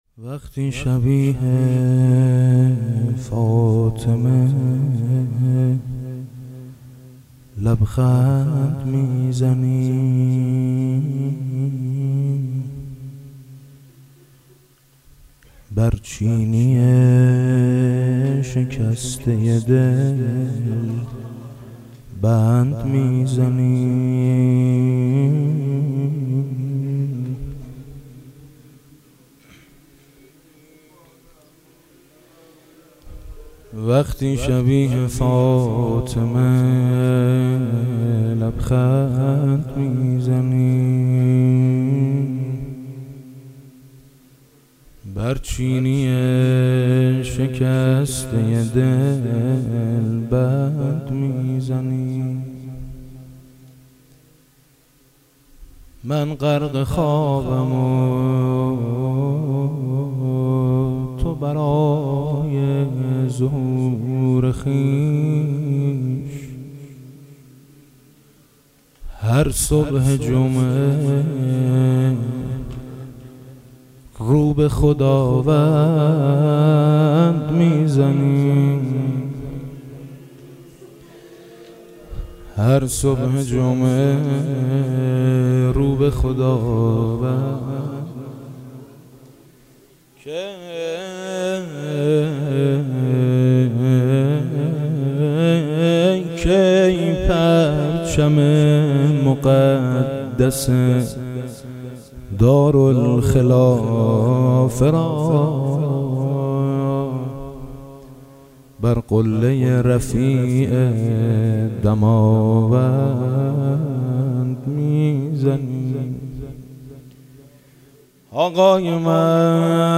نجوا با امام زمان(عج) - ميثم مطيعي در هيئت شهداي گمنام
میثم مطیعی مداحی امام زمان